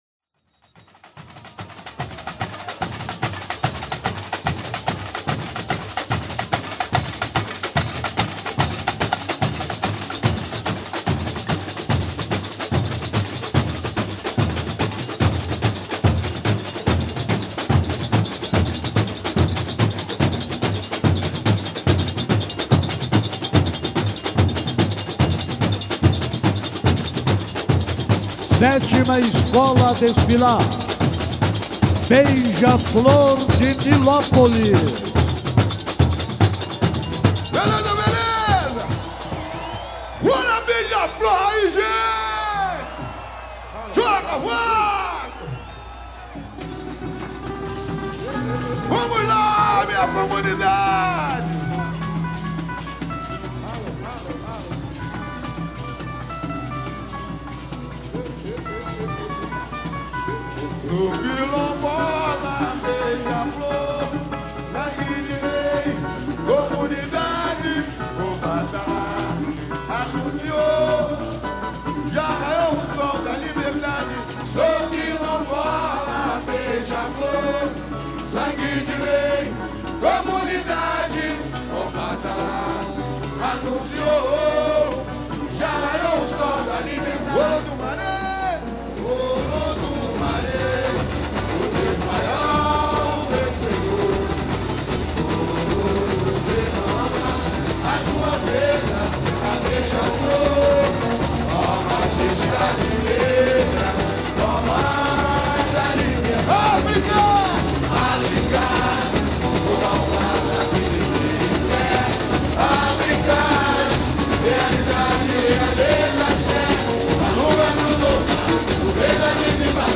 cantou com muita garra do começo ao fim